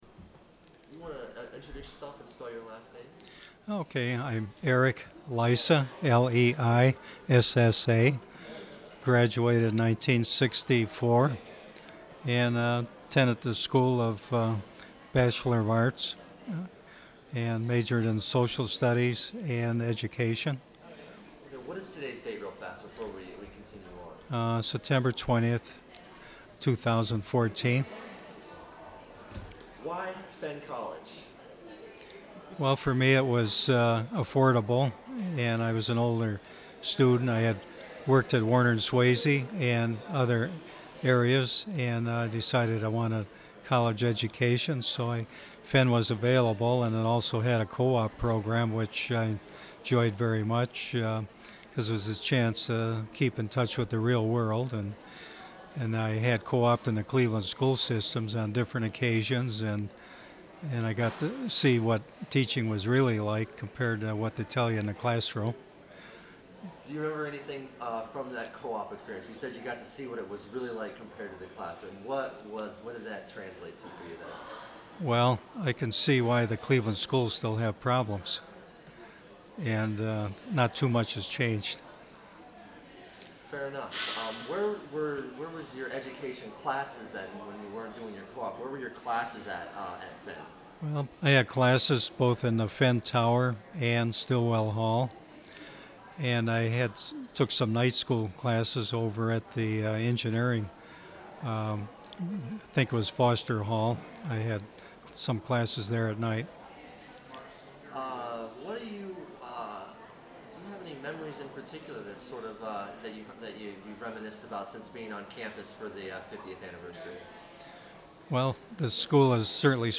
Oral history interviews with select alumni, trustees, faculty, and other friends of Cleveland State University, conducted by staff at the CSU Center for Public History and Digital Humanities in coordination with the Office of the President and Office of Alumni Affairs on the occasion of CSU's 50th anniversary.
Interview